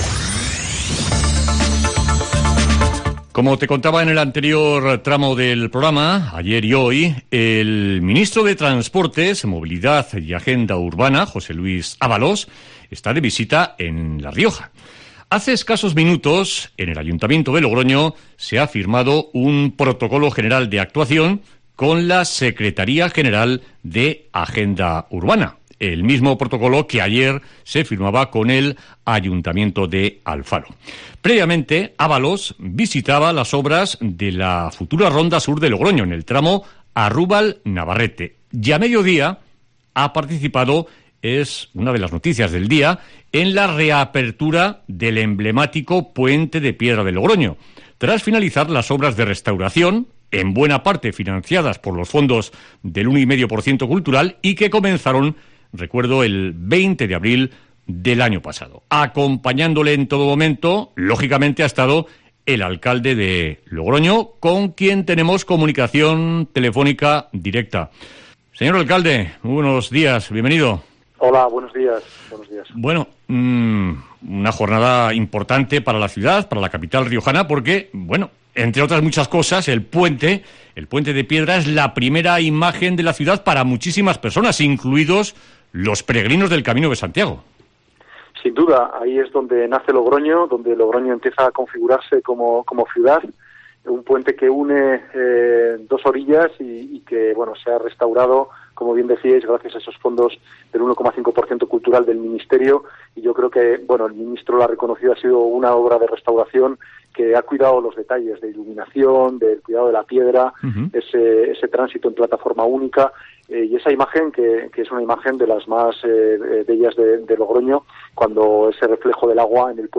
Entrevista en COPE Rioja al alcalde de Logroño, Pablo Hermoso de Mendoza